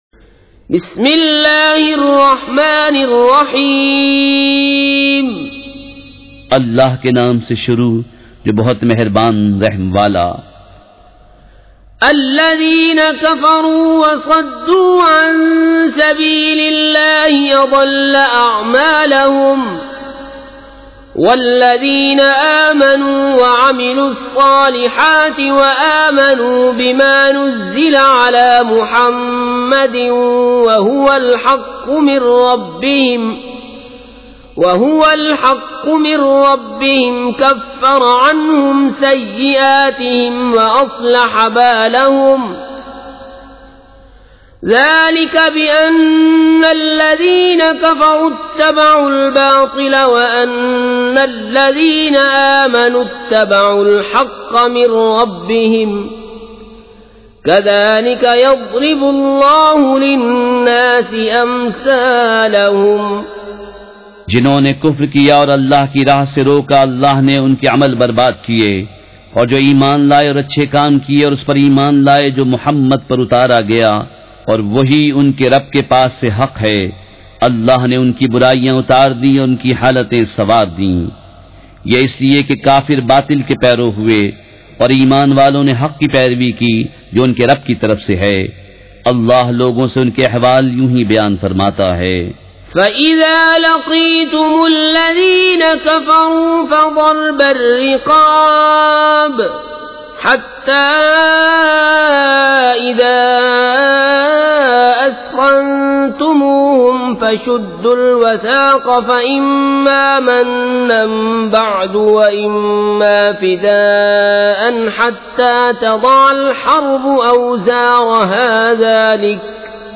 سورۂ محمد مع ترجمہ کنز الایمان ZiaeTaiba Audio میڈیا کی معلومات نام سورۂ محمد مع ترجمہ کنز الایمان موضوع تلاوت آواز دیگر زبان عربی کل نتائج 2021 قسم آڈیو ڈاؤن لوڈ MP 3 ڈاؤن لوڈ MP 4 متعلقہ تجویزوآراء